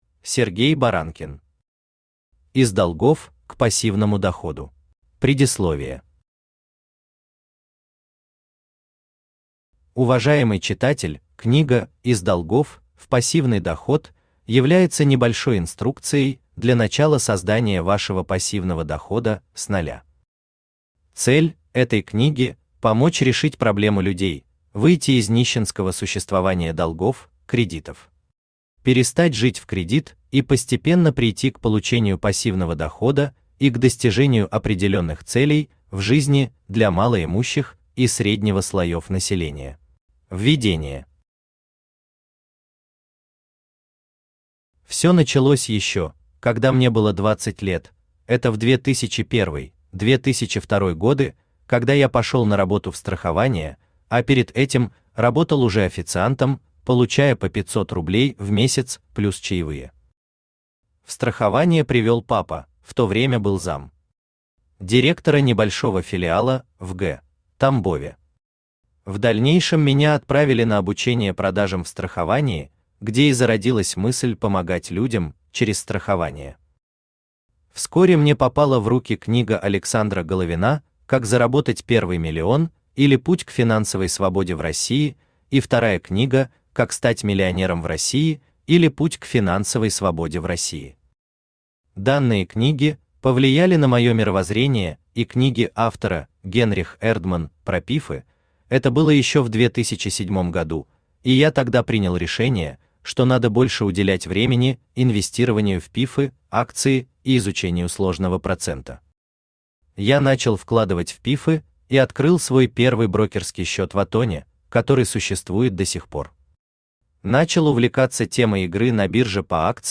Аудиокнига Из долгов к пассивному доходу | Библиотека аудиокниг
Aудиокнига Из долгов к пассивному доходу Автор Сергей Валентинович Баранкин Читает аудиокнигу Авточтец ЛитРес.